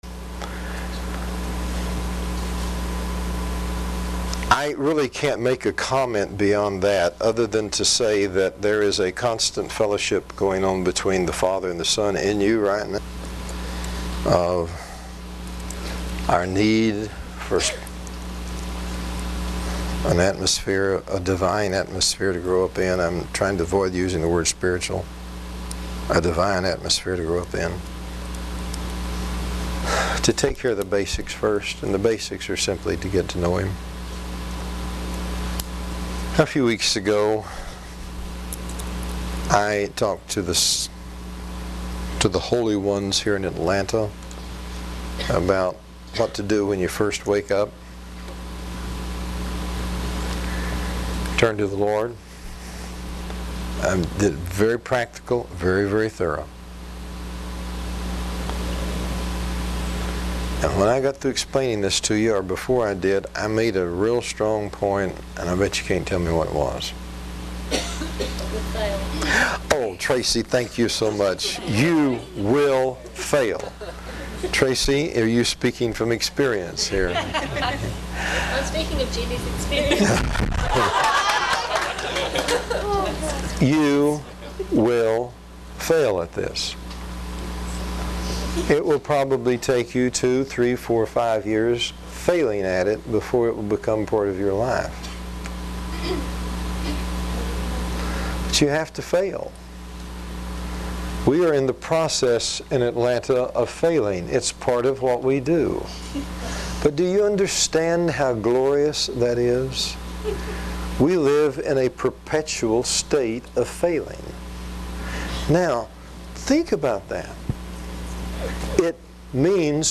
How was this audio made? Atlanta 1998 Conference – Jesus Touches His Father Part 3